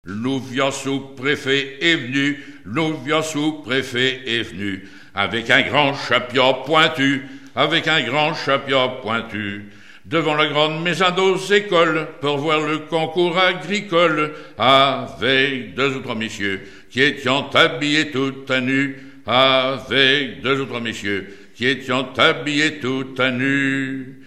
Lettrées patoisantes
Pièce musicale inédite